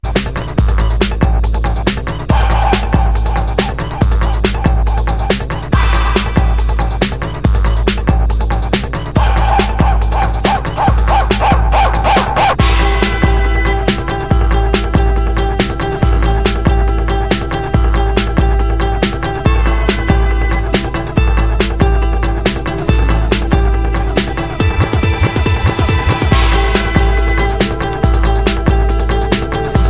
Breakbeat / Progressive House / Techno Lp Reissue